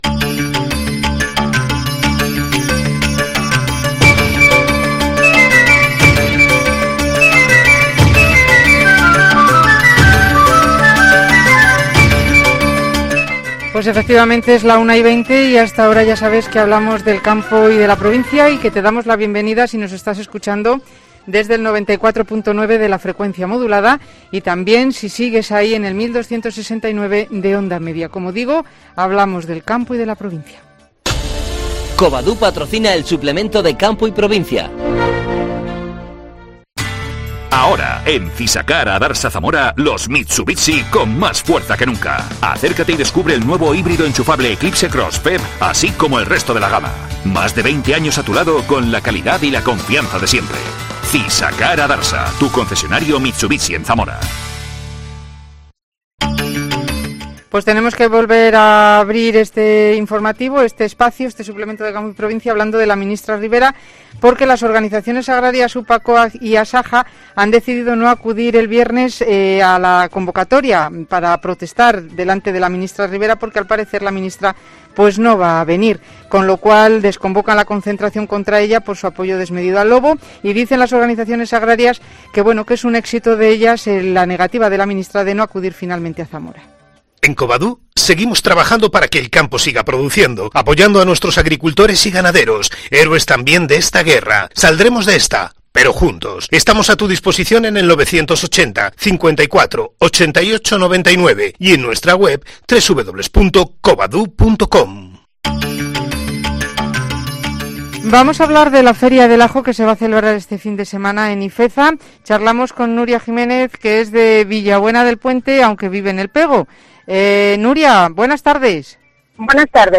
AUDIO: Charla con una ajera del PegoConsultorio Agrario